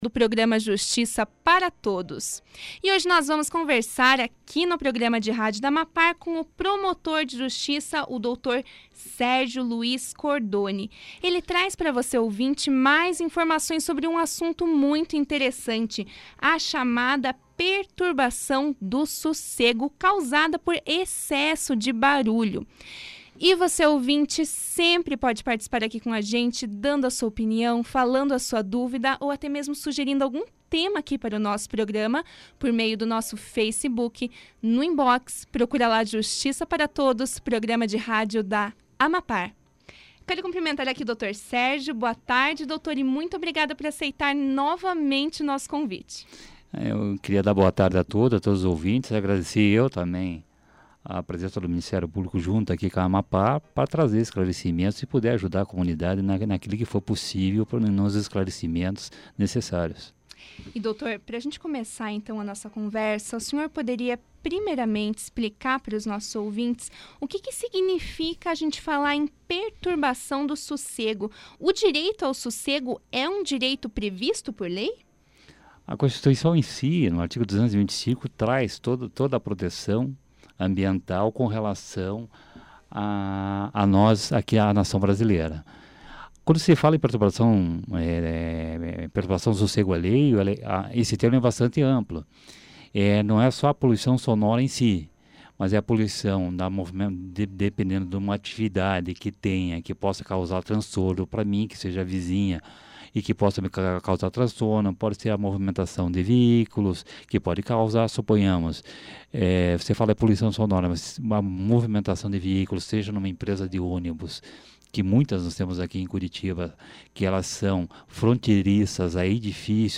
Perturbação do sossego causada por excesso de barulho foi tema tratado pelo programa de rádio da AMAPAR, Justiça para Todos, na quinta-feira (18). Quem trouxe mais informações e esclarecimento sobre o assunto, foi o procurador de justiça Sérgio Luiz Cordoni.
Na oportunidade, Sérgio também deu orientações de como o cidadão pode realizar denúncias e apontou as principais consequências negativas decorrentes da poluição sonora. Confira aqui a entrevista na íntegra.